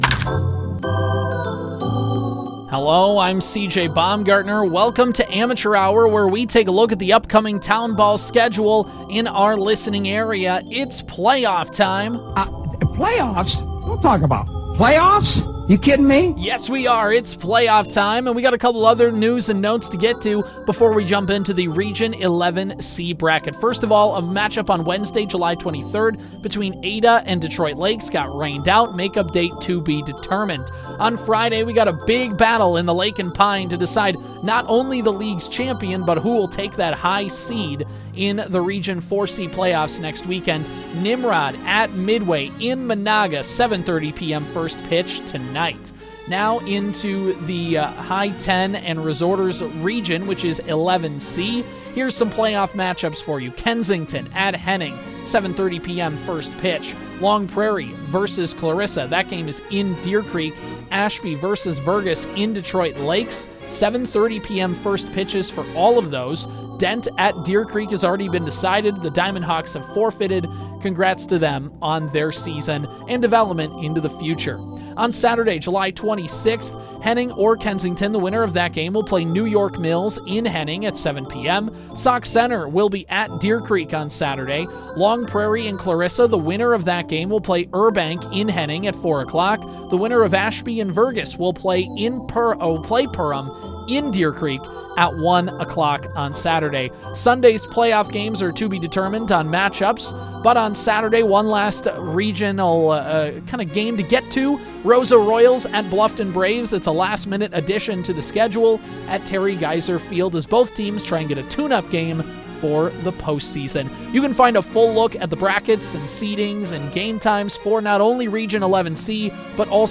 Local Sports | Sports